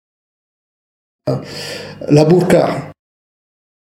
uitspraak La burqa voorbeeld On en a eu une ici qu'on a eu d'un client.